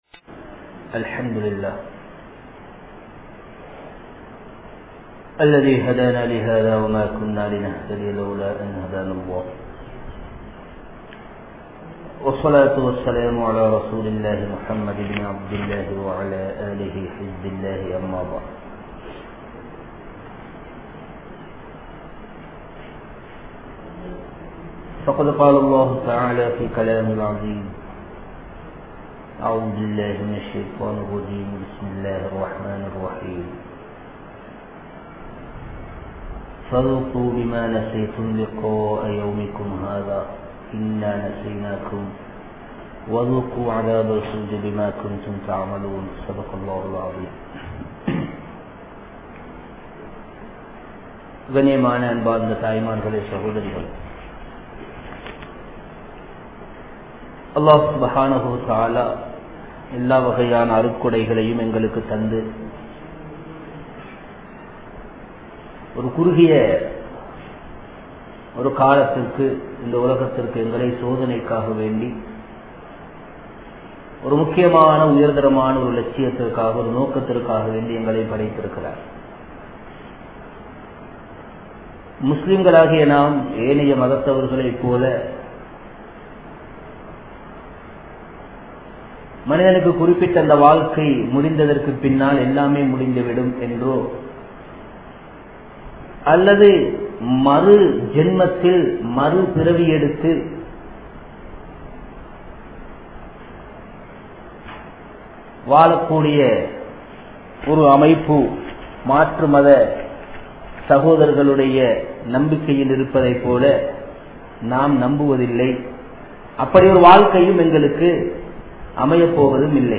Manithan Marakka Koodaathavai Aanaal Indru Maranthu Vittaan (மனிதன் மறக்கக் கூடாதவை ஆனால் இன்று மறந்து விட்டான்) | Audio Bayans | All Ceylon Muslim Youth Community | Addalaichenai
Masjidhul Asma